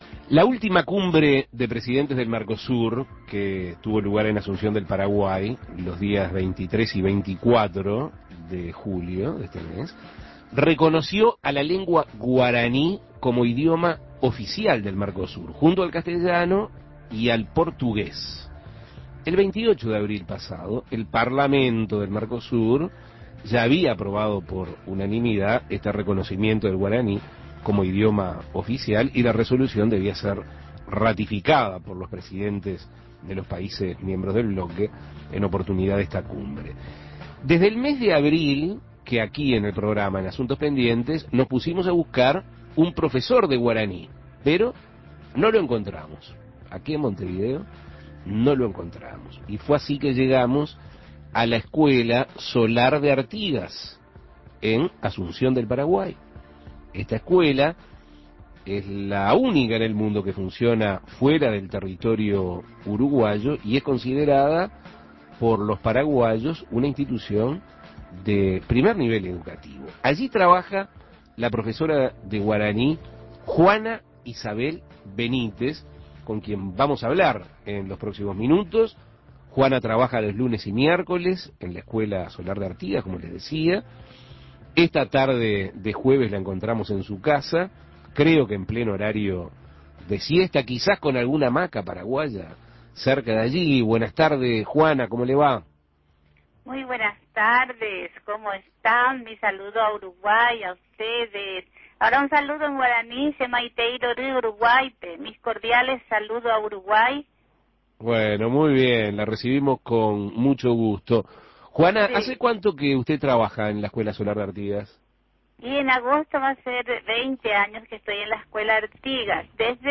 Entrevistas Guaraní, una lengua del Mercosur Imprimir A- A A+ La última Cumbre de Presidentes del Mercosur reconoció a la lengua guaraní como idioma oficial del Mercosur junto al castellano y al portugués.